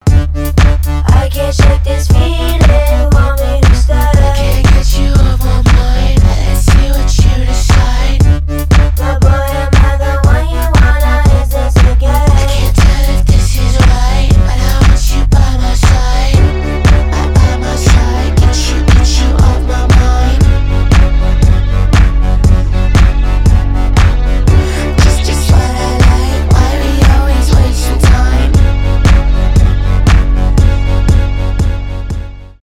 electronic
indie pop